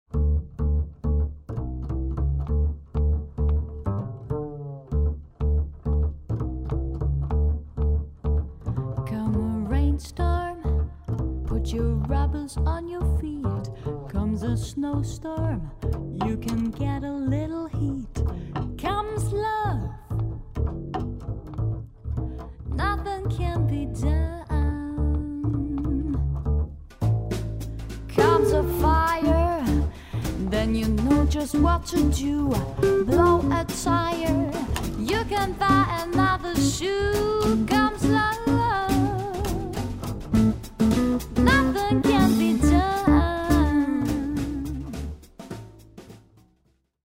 Jazz-Quartett
Groove Jazz
Trio (Gitarre, Bass, Drums)